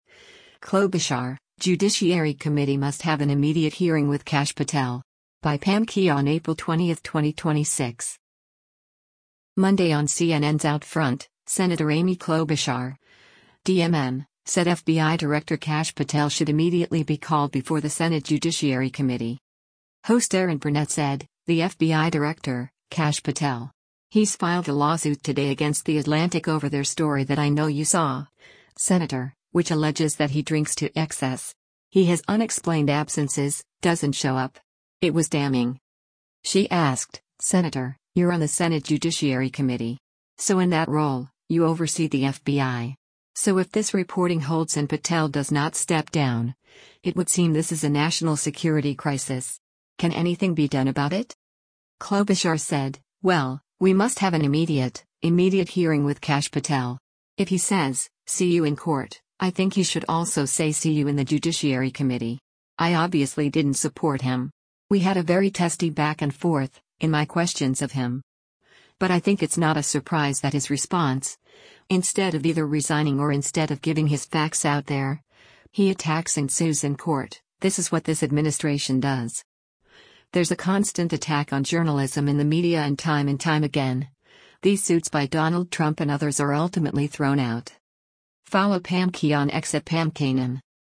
Monday on CNN’s “OutFront,” Sen. Amy Klobuchar (D-MN) said FBI Director Kash Patel should immediately be called before the Senate Judiciary Committee.